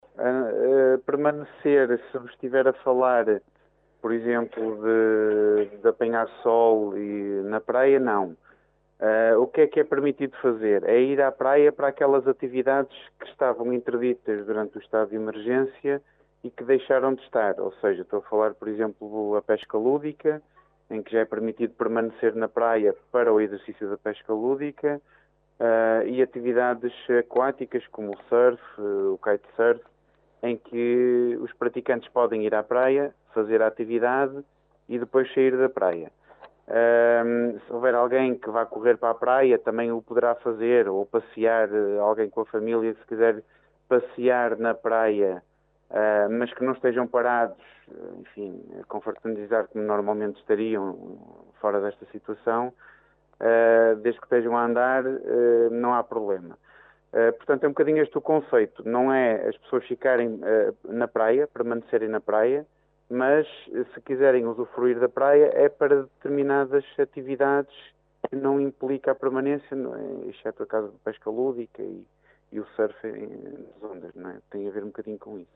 Em declarações ao Jornal C, o Capitão do Porto e Comandante Local da Polícia Marítima de Caminha, Pedro Cervaens Costa, explicou o que se pode e não pode fazer, nas praias concelhias.